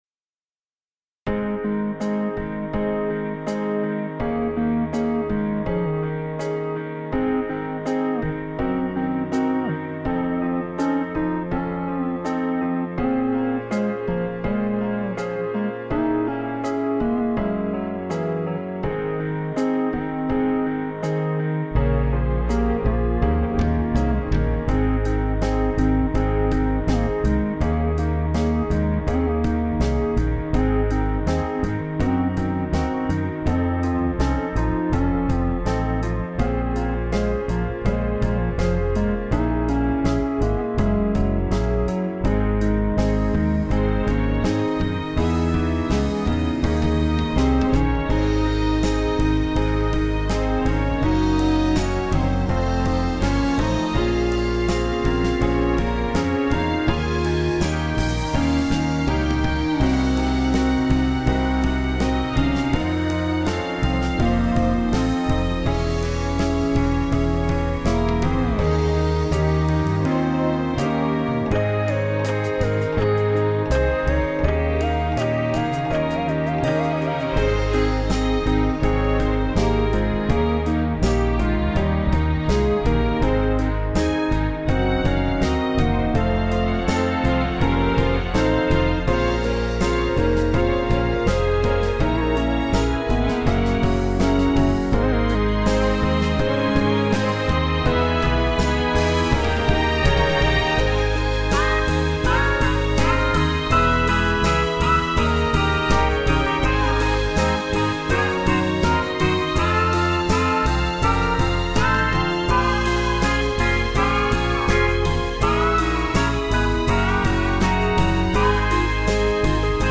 Here's a pop tune I composed using the Sonar 8.5 piano roll (that's how I make all my stuff). It's inspired by the 1970's era of pop songs.